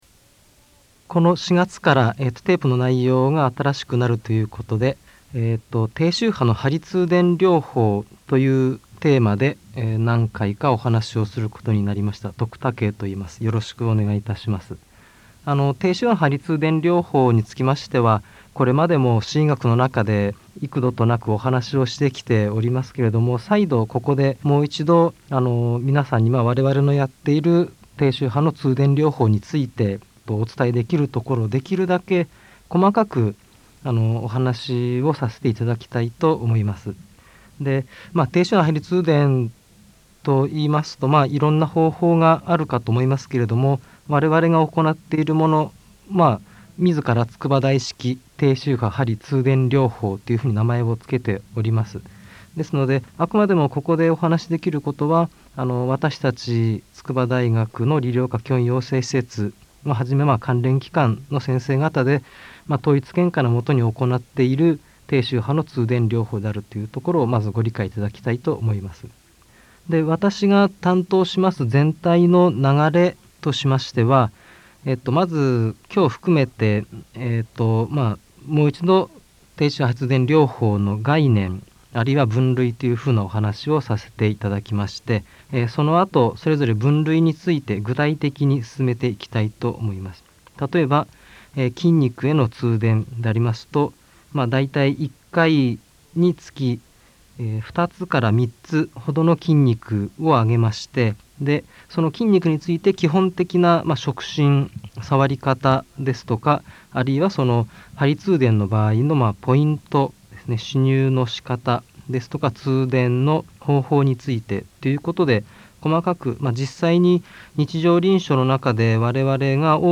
2019年4月9日 2019年4月15日 鍼通電療法とは、どういうものなのか？ 初心者の方に向けて、基本的なことをお話ししています。 社会福祉法人 日本点字図書館様作成の1999年4月のテープ雑誌 新医学より一部抜粋したものです。